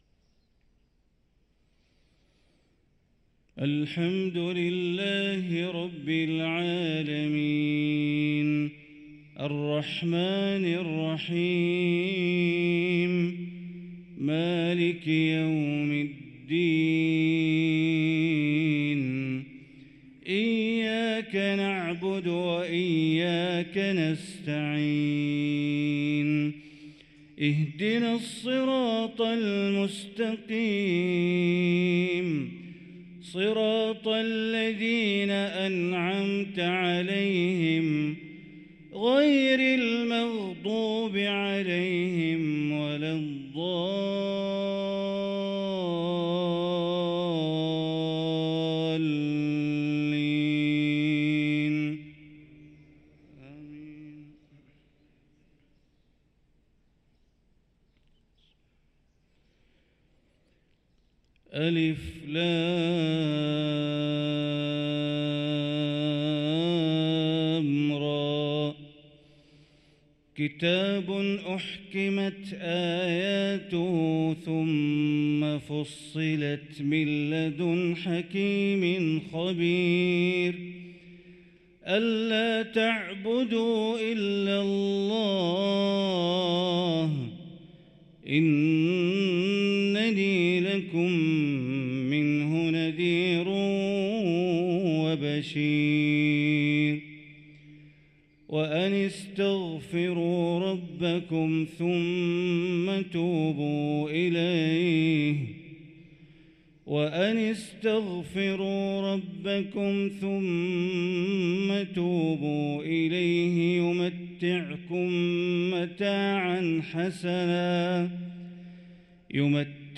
صلاة الفجر للقارئ بندر بليلة 11 صفر 1445 هـ
تِلَاوَات الْحَرَمَيْن .